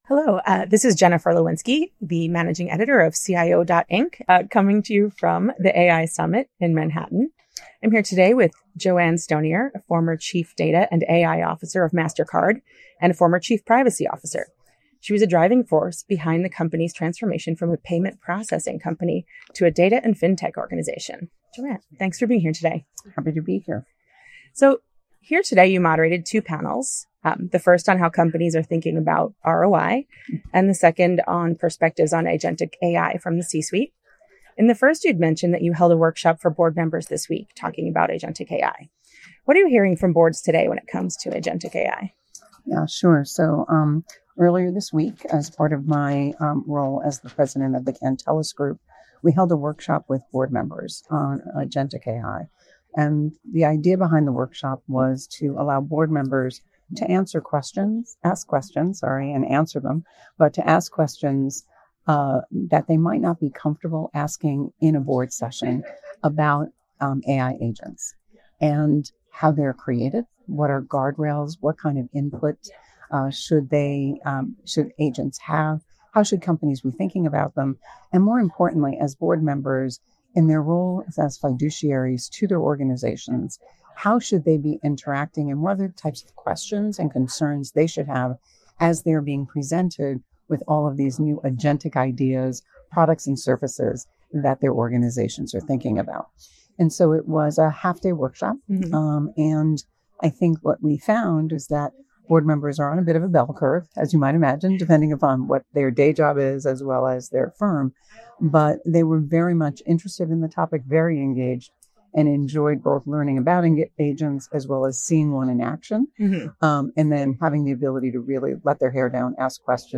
Exclusive, insightful audio interviews by our staff with data breach/security leading practitioners and thought-leaders
Audio interviews with information security professionals.